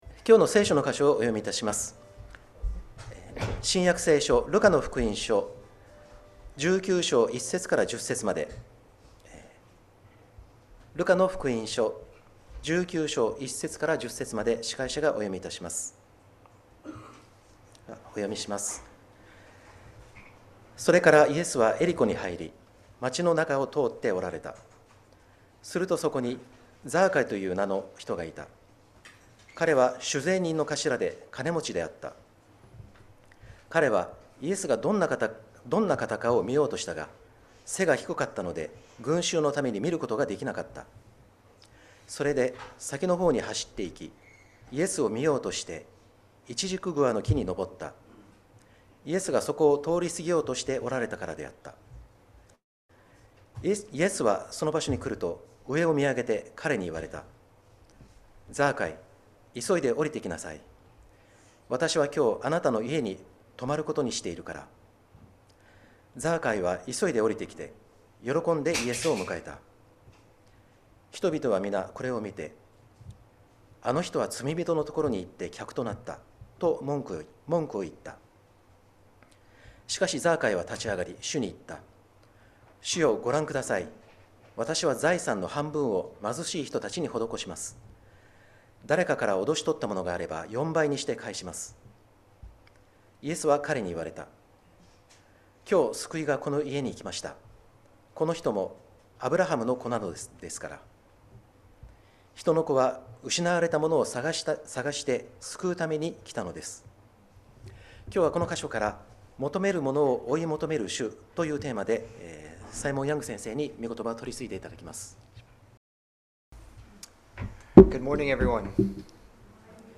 2025年7月27日礼拝 説教 「求める者を追い求める主」Seeking the Seeker